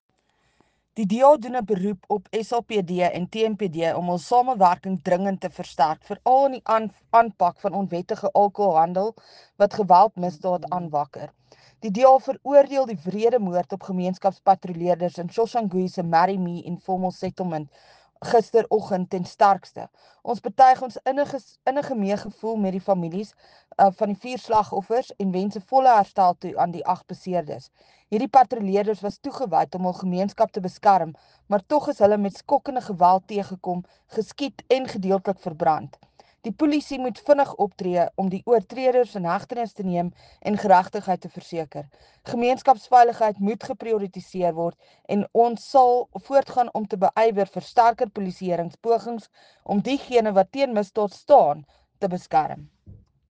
Note to Editors: Please find the attached soundbites in